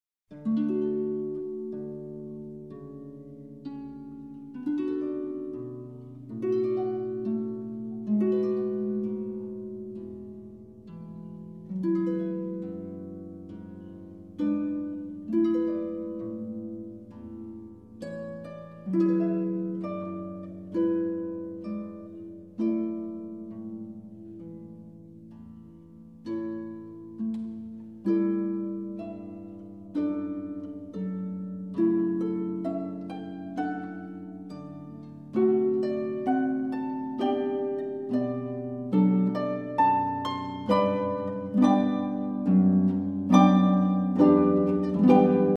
Fassung / Version: Klavier, Solo-Harfe oder Orgel.
The piece itself is almost entirely free of dissonance.
Version for Solo-Harp